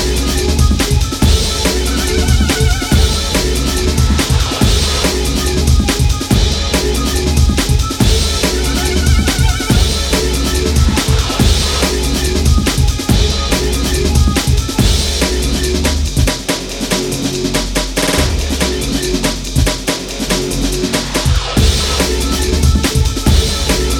no Backing Vocals Dance 3:47 Buy £1.50